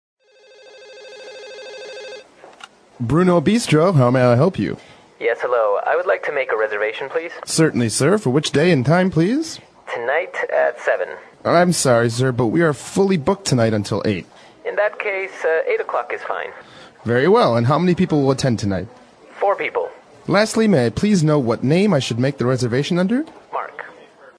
外教讲解纯正地道美语|第341期:Making A Dinner Reservation 晚餐预定